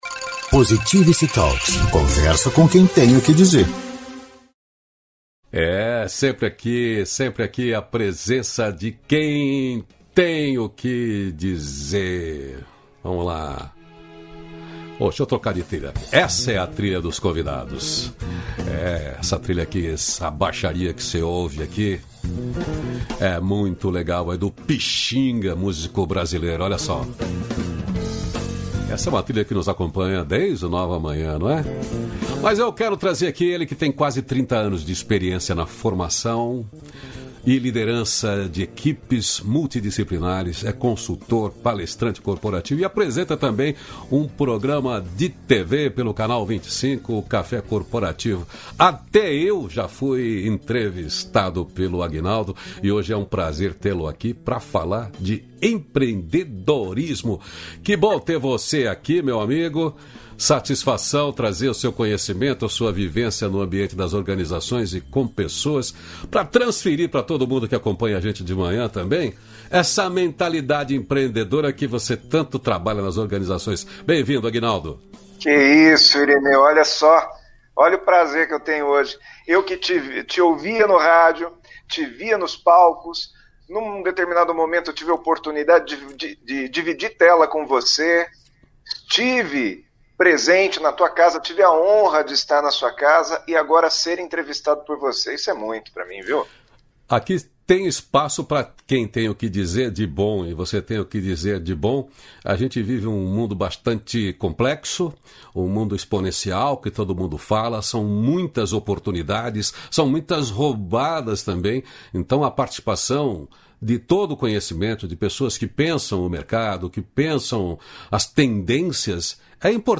277-feliz-dia-novo-entrevista.mp3